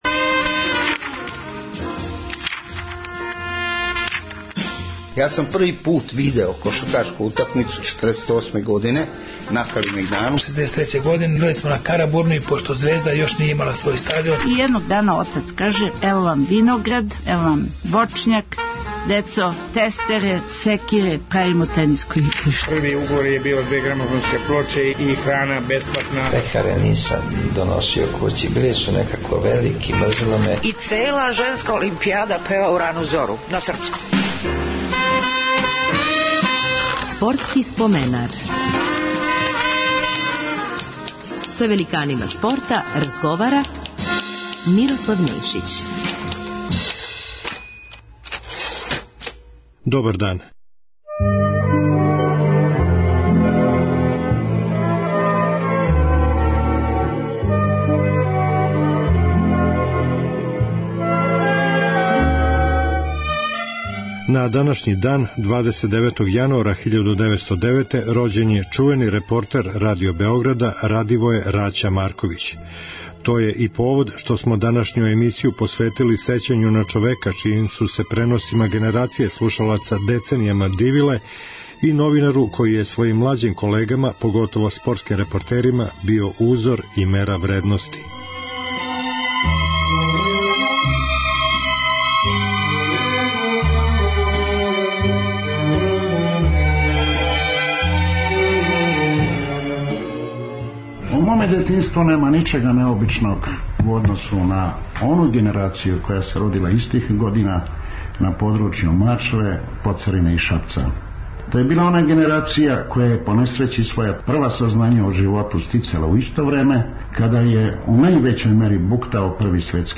Користећи материјал из нашег Тонског архива биће речи: о одрастању Радивоја Марковића у време Првог светског рата, његовим почецима интересовања за спорт, начину припремања за пренос, документацији коју је користио у свом репортерском раду, грешкама, тешкоћама репортерског посла, неочекиваним догађајима током преноса. треми, односу према слушаоцима, занимљивостима, како је настало његово чувено „Гол, гол, гол!" које се користи за шпицу емисије „Време спорта и разоноде"...